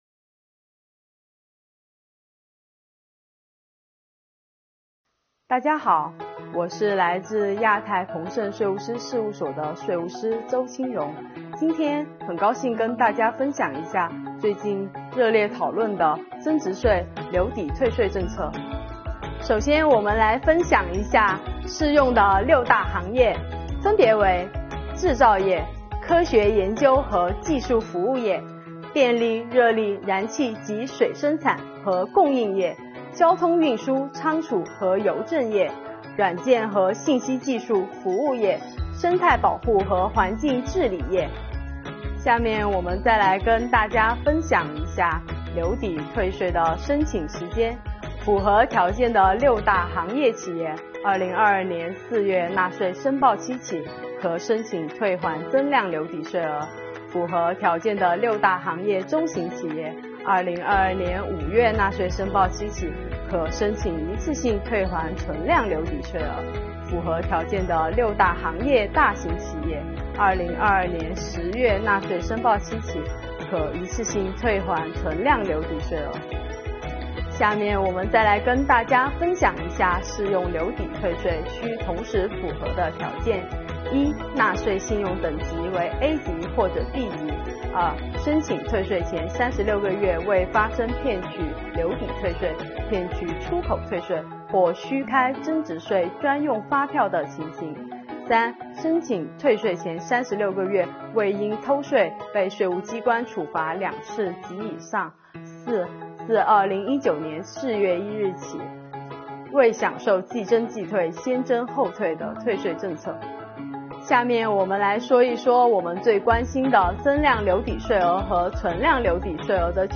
视频|税务师聊税——掌握这些关键点，增值税留抵退税轻松办！